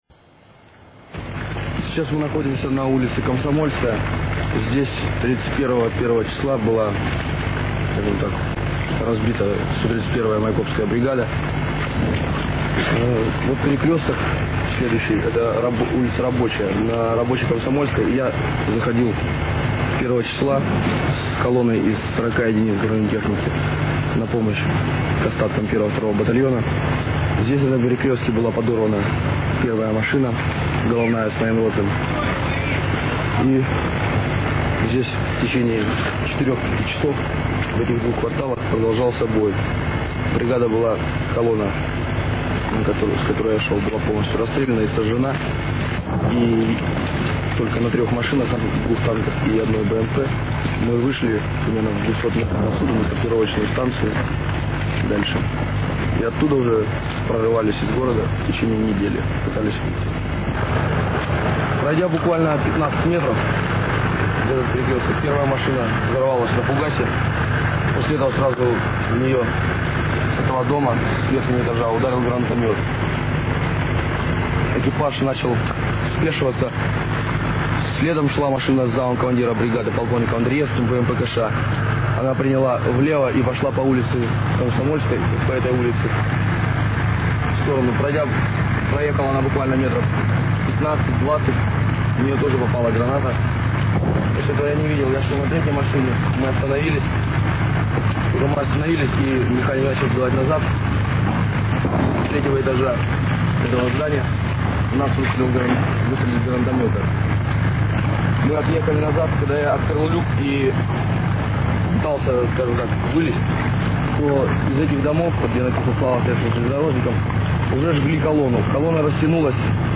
Эта запись представляет собой фрагмент из фонограммы любительской съёмки, которая была сделана в Грозном 5 февраля 1995 года.
Запись очень низкого качества, поэтому я ограничусь лишь этой фонограммой.